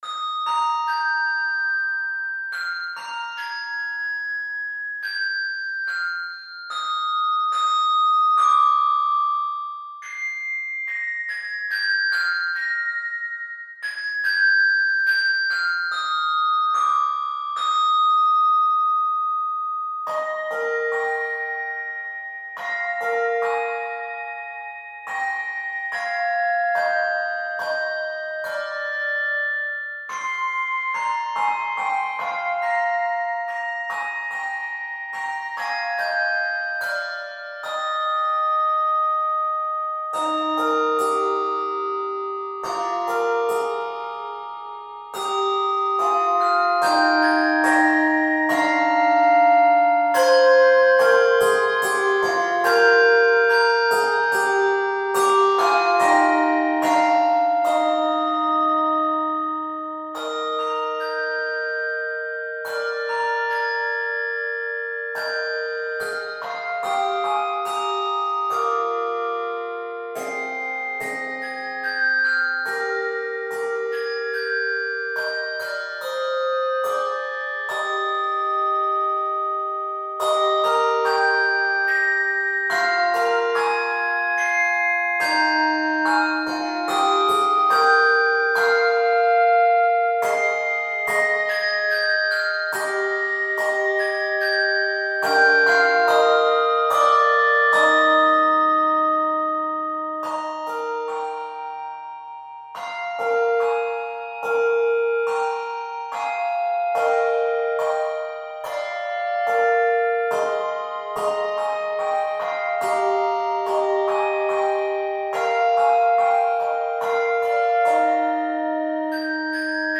Key of Eb Major.